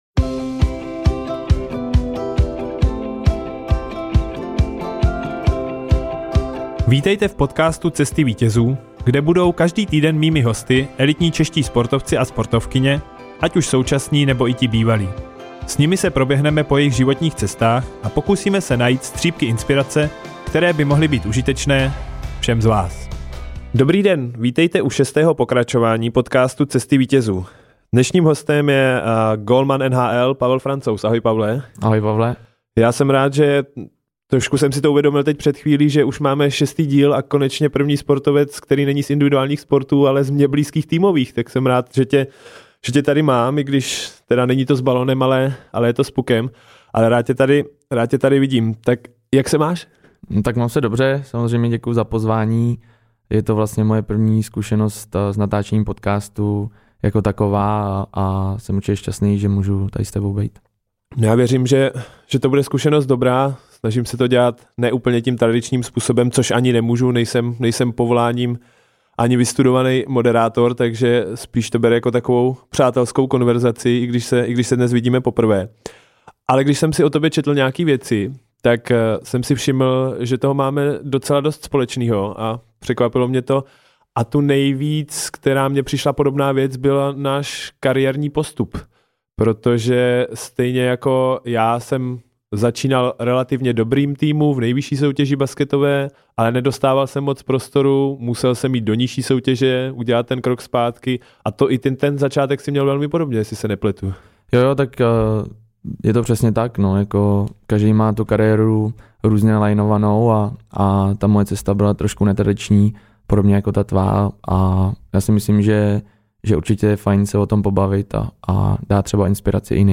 Hostem šestého dílu byl hokejový gólman Colorado Avalanche Pavel Francouz. Povídal o krocích zpátky, které musel udělat, aby mohl zase mířit vzhůru a taky proč je jeho vzorem Tomáš Garrigue Masaryk.